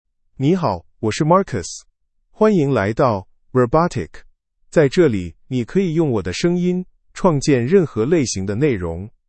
Marcus — Male Chinese (Mandarin, Simplified) AI Voice | TTS, Voice Cloning & Video | Verbatik AI
Marcus is a male AI voice for Chinese (Mandarin, Simplified).
Voice: MarcusGender: MaleLanguage: Chinese (Mandarin, Simplified)ID: marcus-cmn-cn
Voice sample
Marcus delivers clear pronunciation with authentic Mandarin, Simplified Chinese intonation, making your content sound professionally produced.